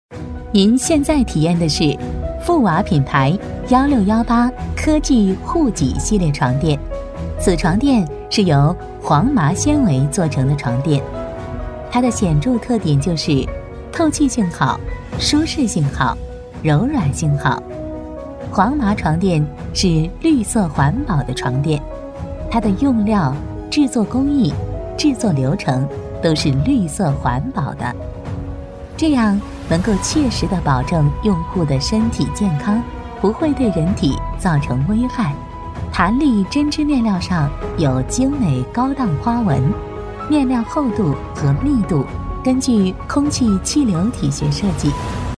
女66-产品解说【富娃家居 温馨柔和】
女66-产品解说【富娃家居 温馨柔和】.mp3